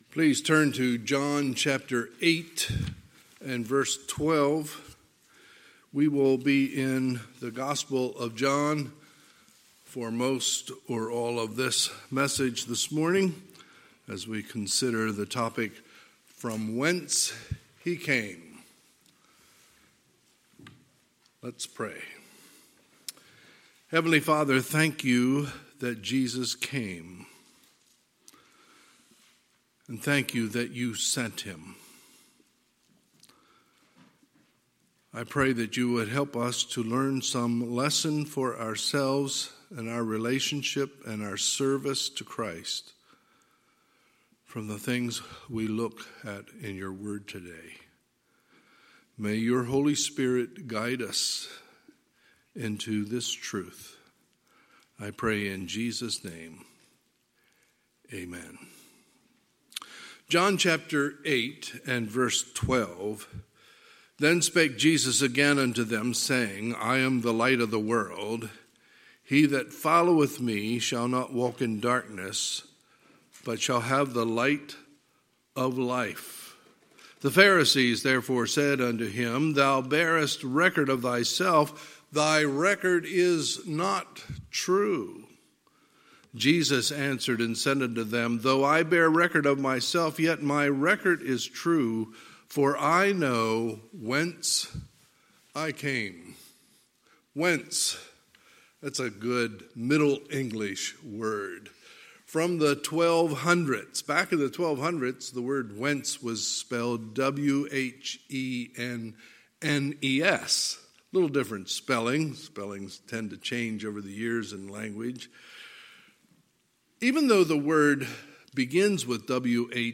Sunday, December 13, 2020 – Sunday Morning Service